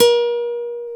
Index of /90_sSampleCDs/Roland L-CD701/GTR_Nylon String/GTR_Nylon Chorus
GTR BRTNYL06.wav